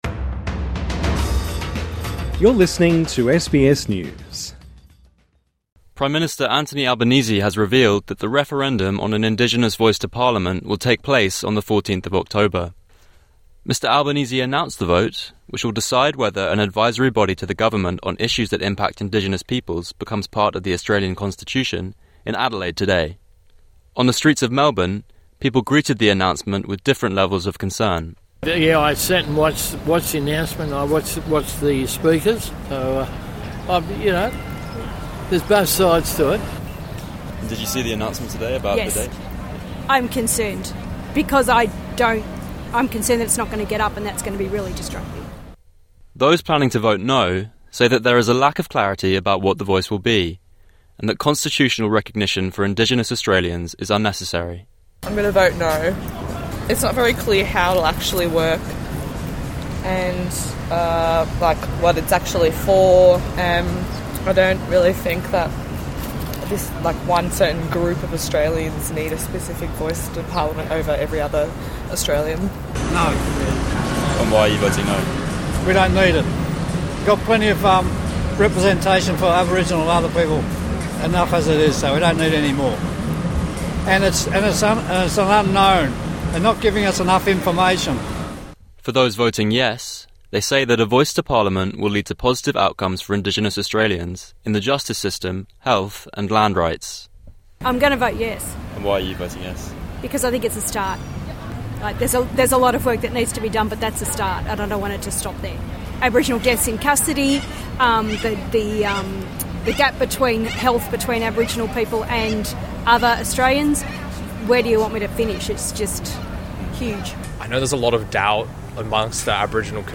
Prime Minister Anthony Albanese has announced that the referendum on an Indigenous Voice to Parliament will take place on the 14th October. SBS went out to see what people in the streets of Melbourne thought.